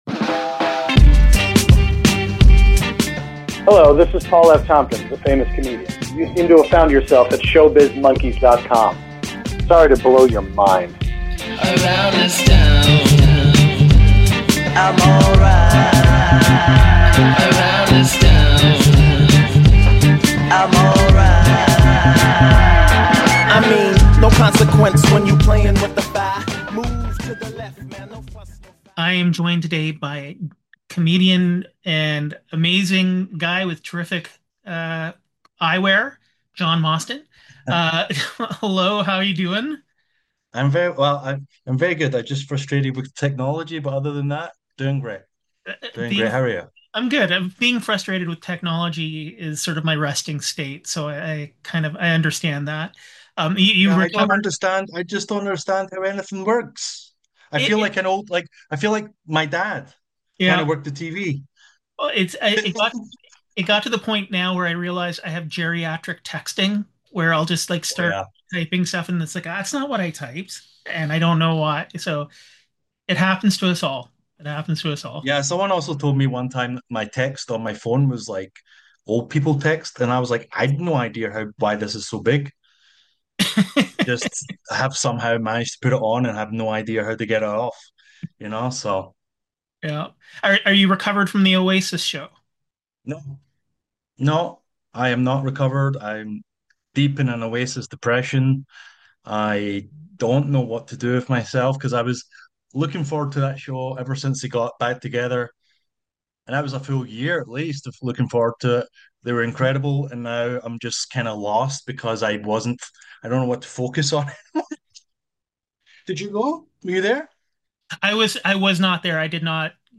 Interview
I got a chance to chat over Zoom with comedian